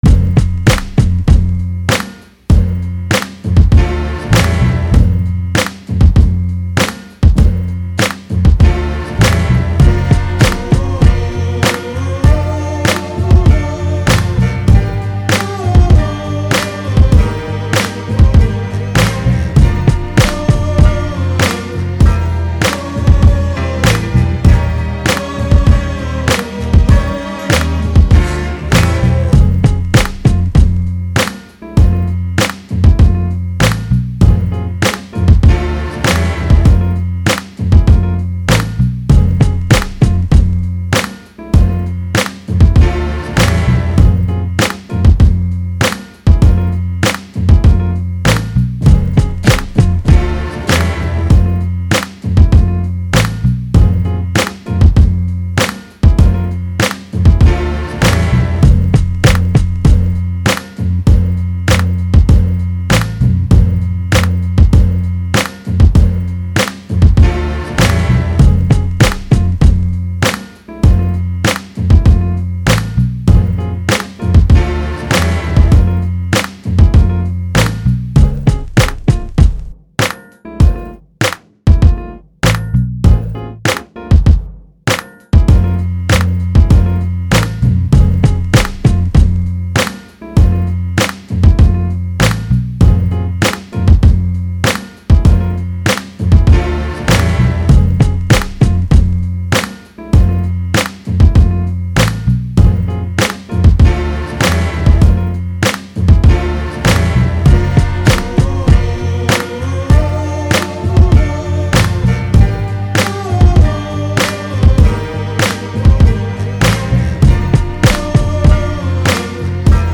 이번 꺼는 2006년에 만들었던 비트입니다 ㅋ
도끼 느낌과 다르게 저는 통통튀는 드럼에 더 집중한 비트입니다 ㅋㅋㅋ